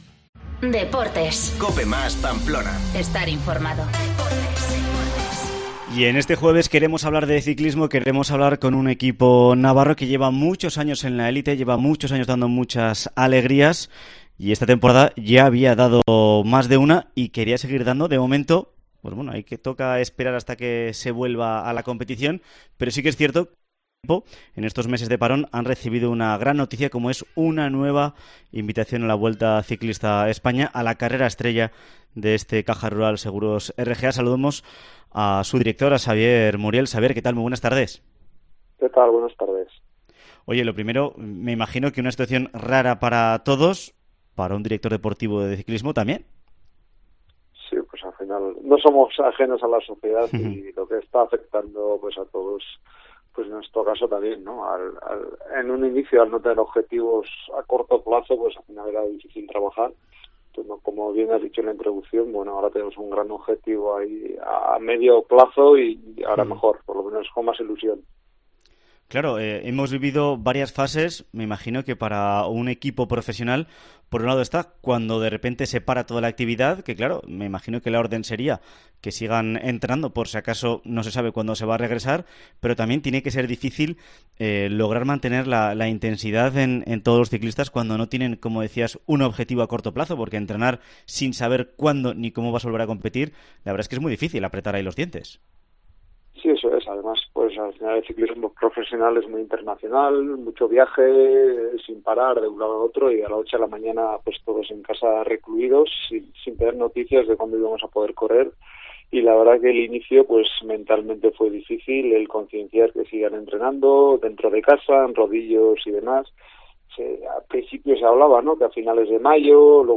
Entrevista con el Caja Rural-Seguros RGA 2020